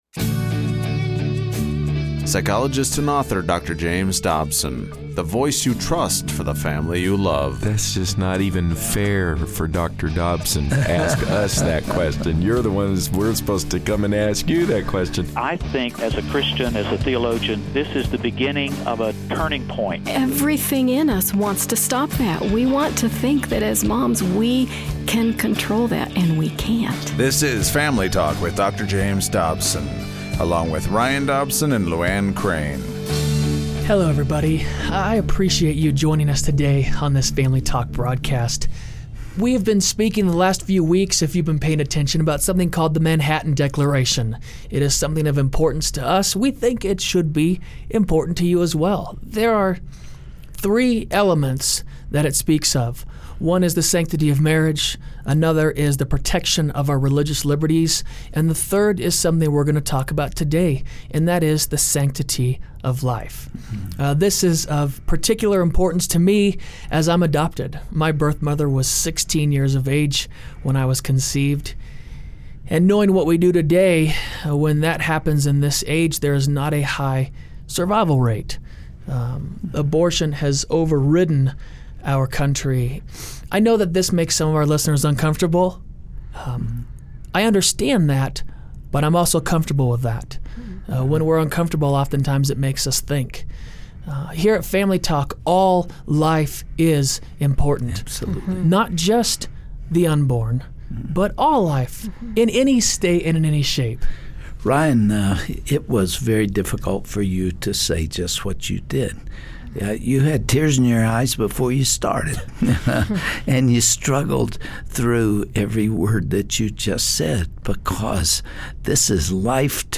Attacks on Christianity are far more often nowadays, and the three issues most often in the cultural crosshairs are 1) religious liberty, 2) the defense of traditional marriage, and 3) the sanctity of life. On this program, you'll hear an impassioned speech by the late pro-life crusader Congressman Henry Hyde, followed by a riveting discussion with Dr. Dobson and the team about what it really means to be a pro-life advocate!